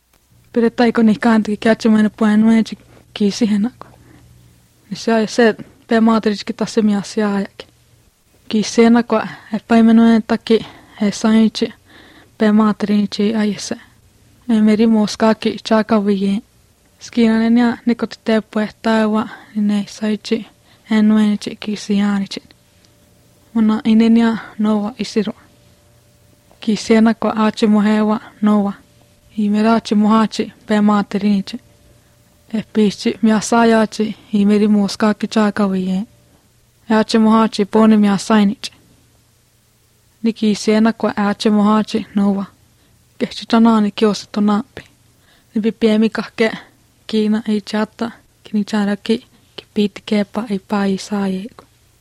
Very pleasant-sounding language, though — my wife commented that it’d be perfect for reading a bedtime story to kids — and it probably isn’t moribund since the speaker sounds young and fluent.
1 September 2015 at 7:43 am I’m not sure why it sounded so “French” to me at first (other than the final glottal stops), maybe because I came straight from lesson 1 of Frantastique and was trying to hear French.